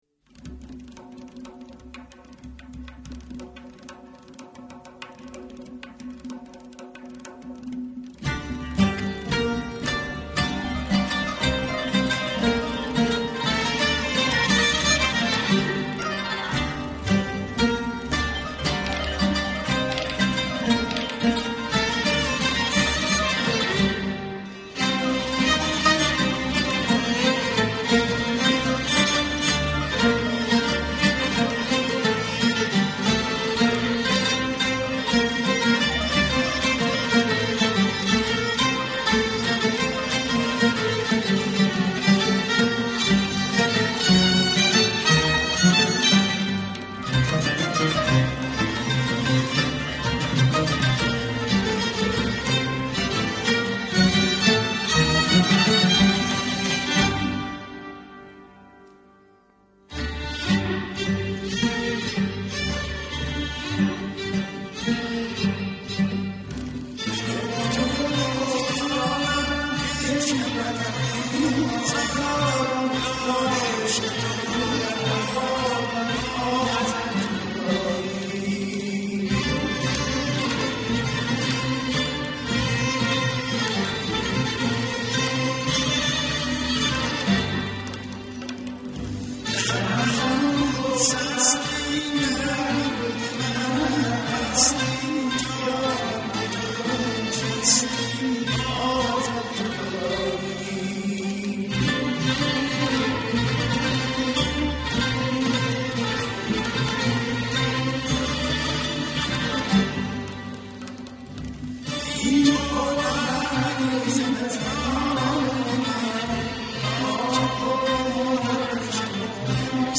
Tasnif